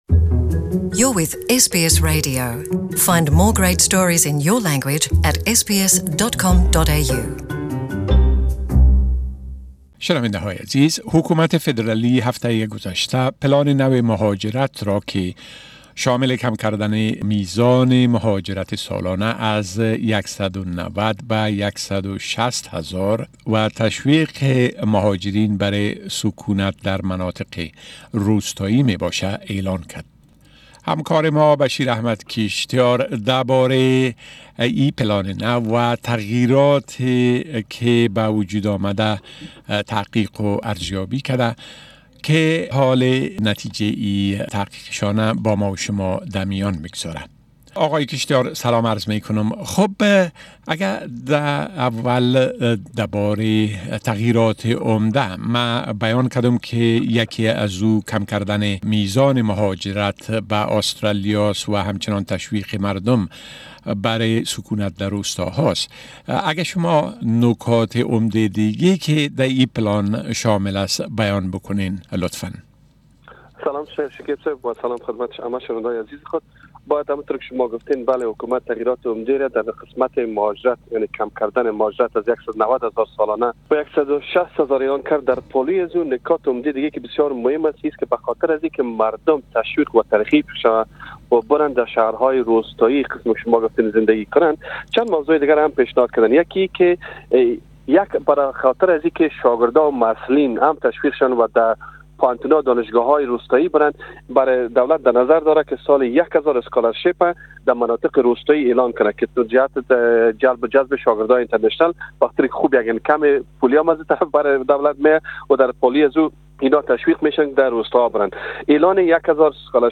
Discussion about New Australian migration plan